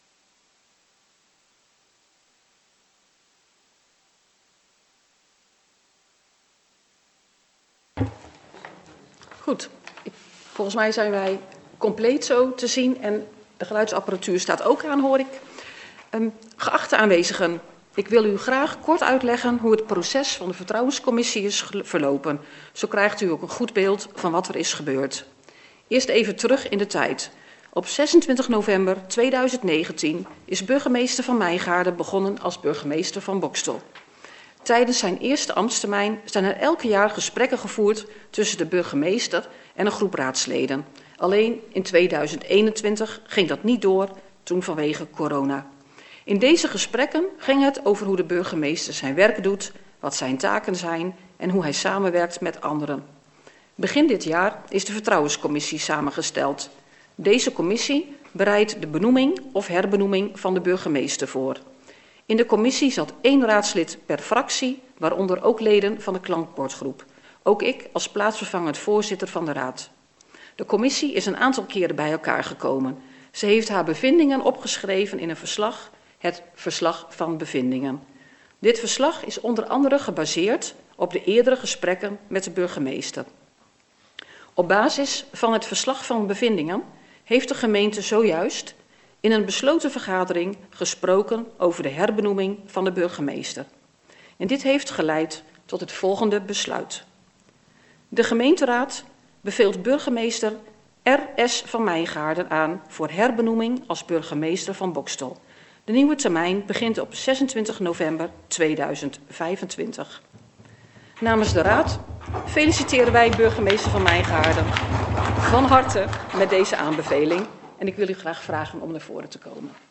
Daarom is afzonderlijk een bestand toegevoegd waarop de geluidsopname van deze raadsvergadering is na te luisteren.